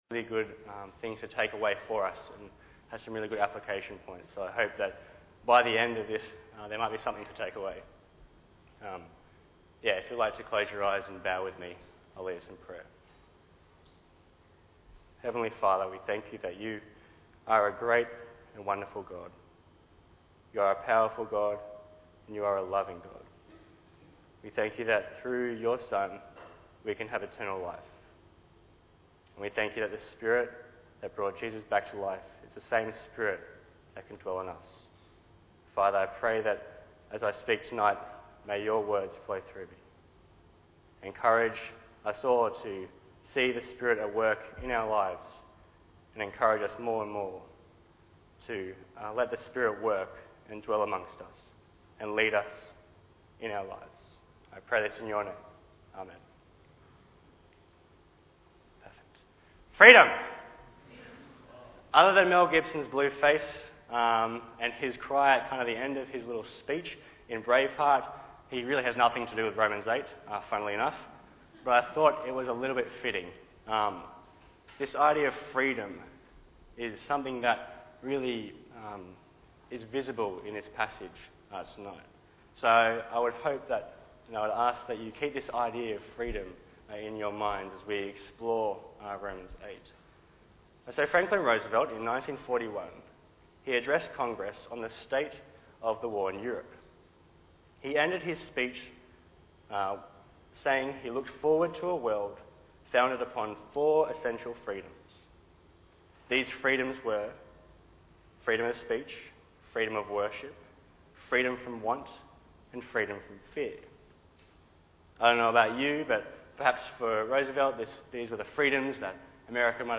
Bible Text: Romans 8:1-17 | Preacher